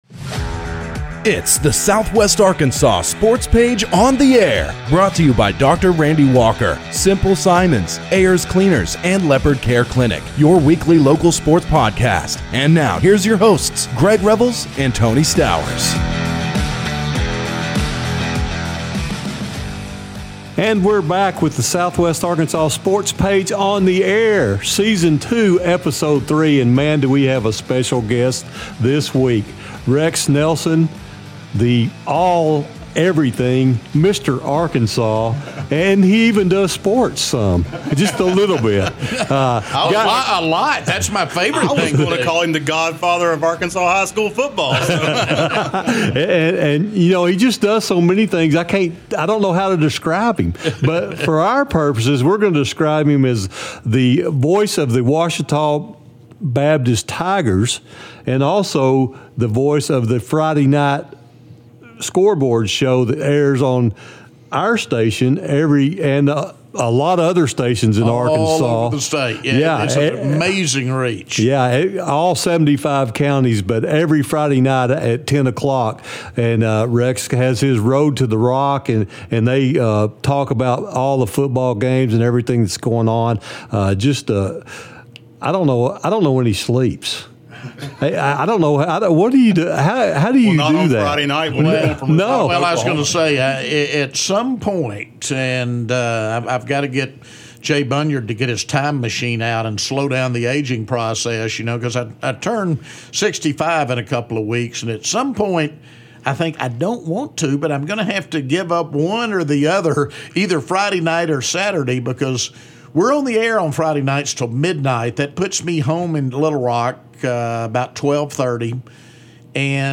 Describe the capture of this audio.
This special sit-down covers a variety of topics including The Hot Springs Baseball Trail, Broadcasting and Highschool Football.